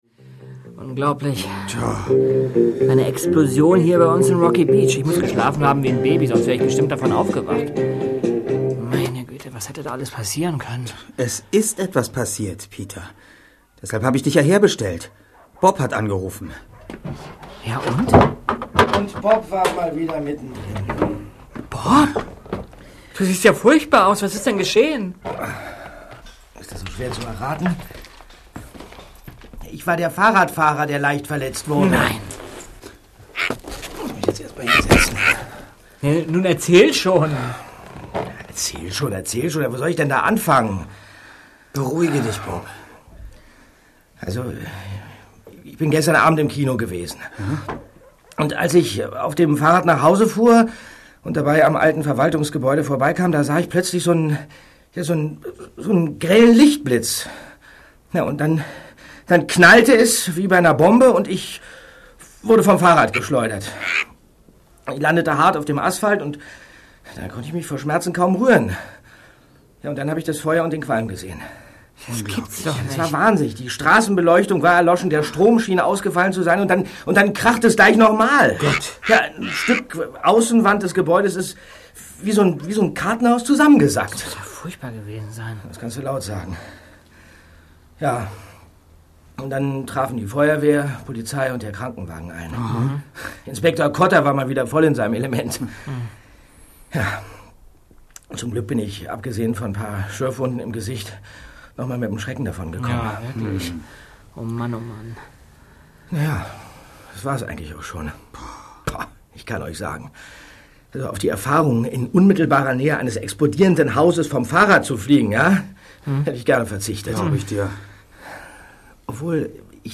Die drei ??? - Feuermond | Physical CD Audio drama
Charles Knox - Dirk Bach
LKW-Fahrer - Nick Heidfeld